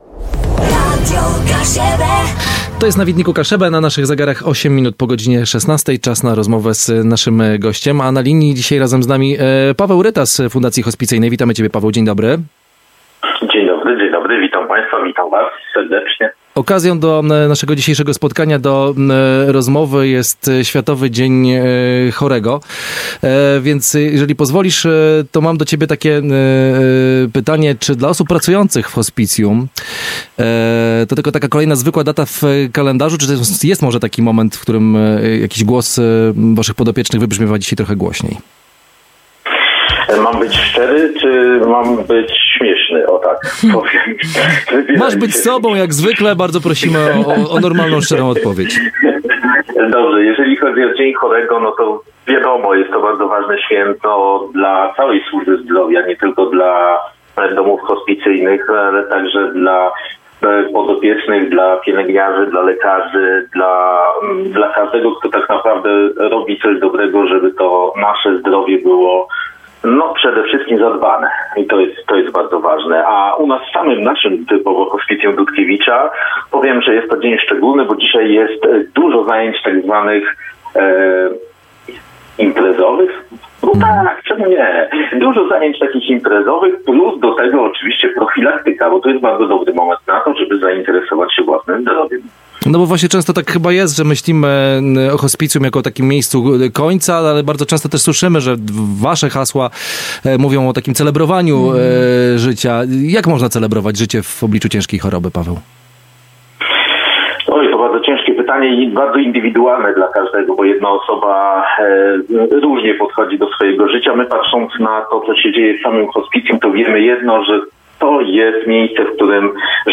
W wywiadzie wybrzmiało to niezwykle silnie – życie w takim miejscu mierzy się siłą do zjedzenia posiłku, uśmiechem czy możliwością wypowiedzenia słowa „kocham”.